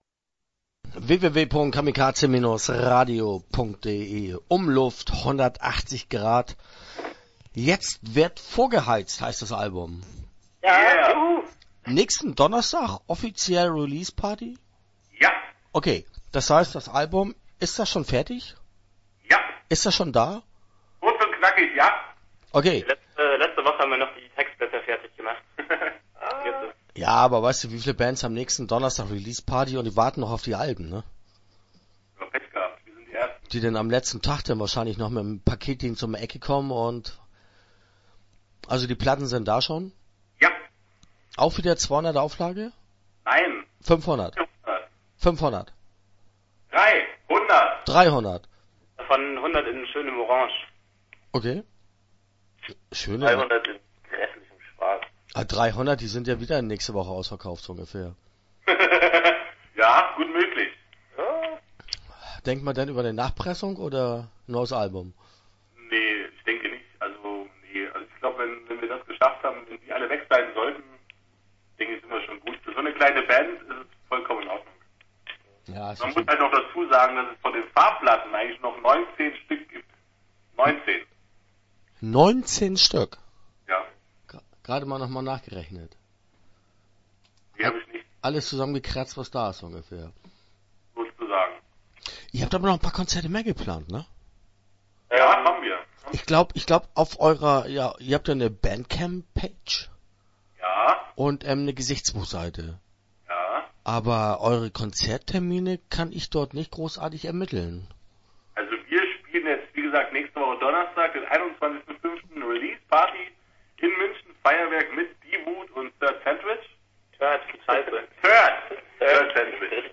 Umluft 180° - Interview Teil 1 (6:59)